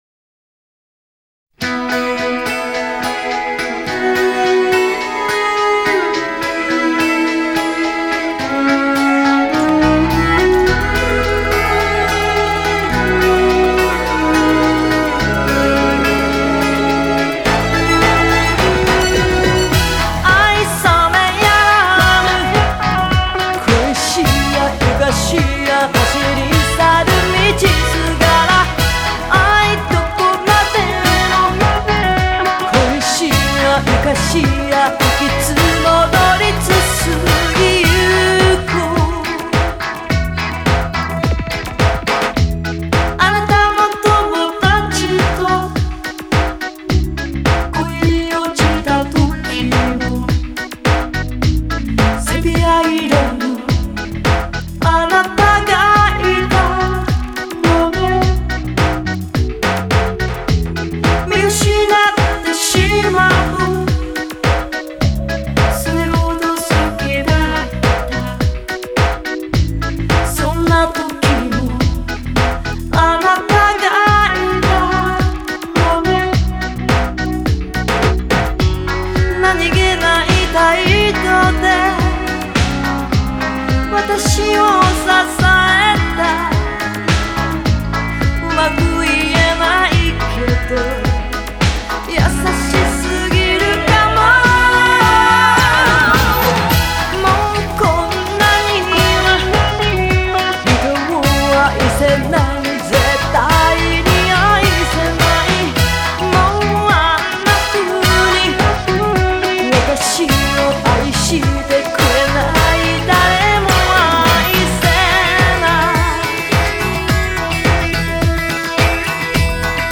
日本演歌